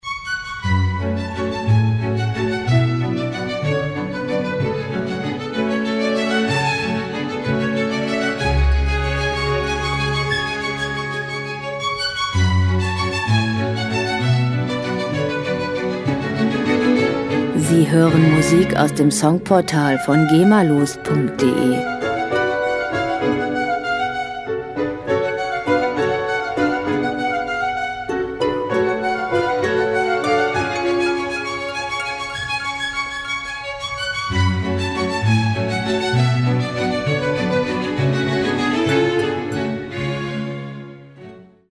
Klassische Musik - Perlen der Klassik
Musikstil: Romantik
Tempo: 188 bpm
Tonart: Fis-Moll
Charakter: lebendig, lyrisch
Instrumentierung: Streicherensemble, Harfe